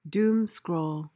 PRONUNCIATION:
(DOOM-skrohl)